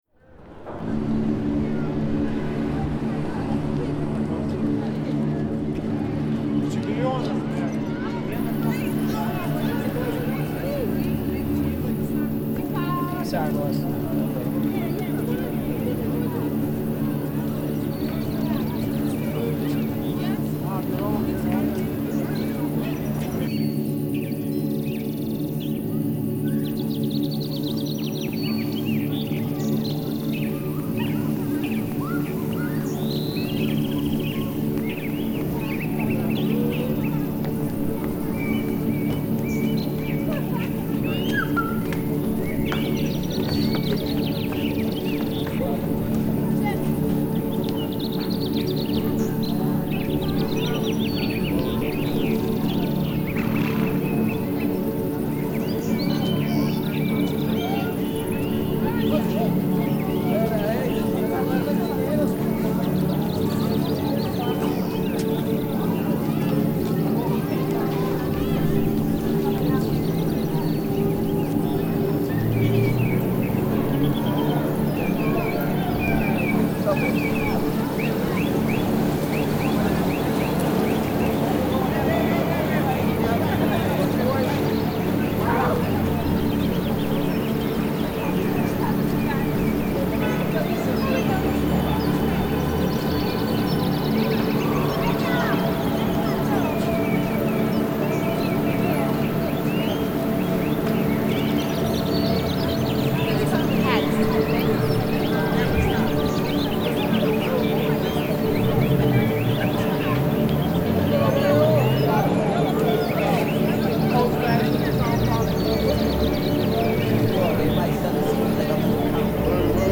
Urban_Spring_Ambiance1
The Modular Active Urban Soundscape.
Urban_Ambiance.mp3